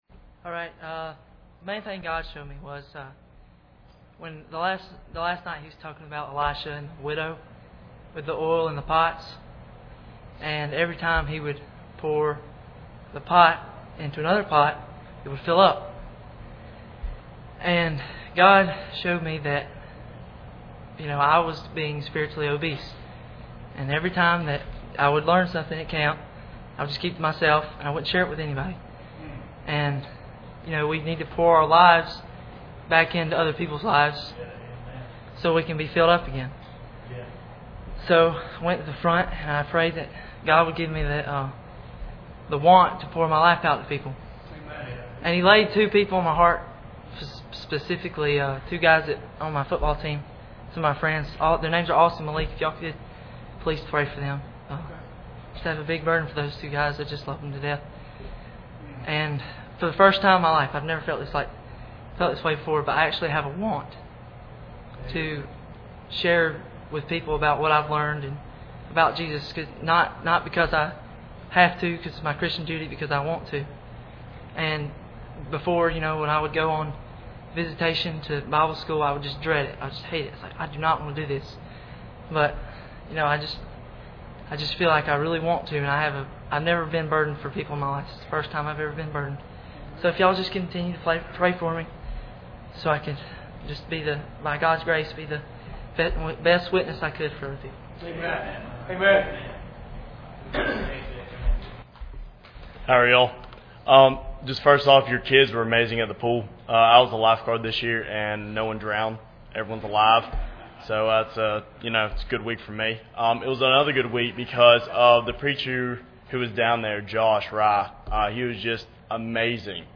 Preacher: CCBC Members | Series: General
Service Type: Sunday Evening